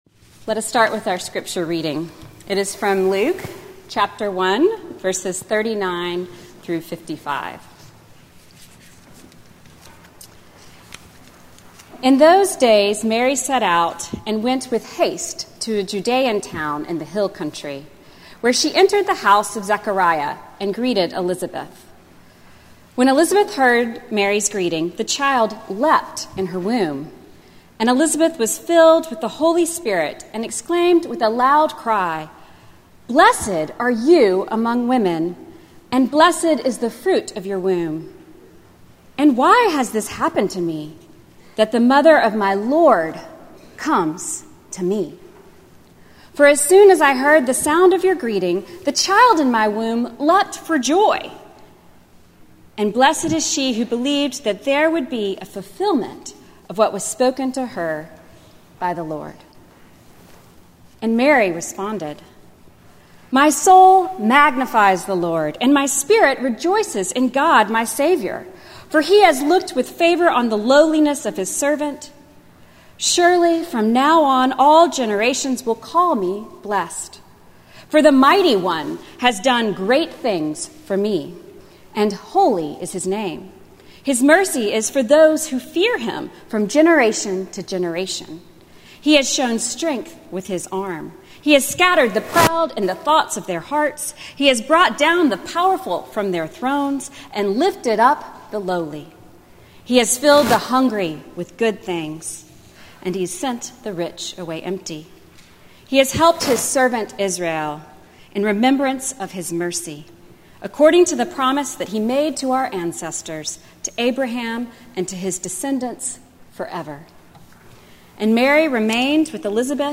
Morningside Presbyterian Church - Atlanta, GA: Sermons: Mother to God
Morningside Presbyterian Church - Atlanta, GA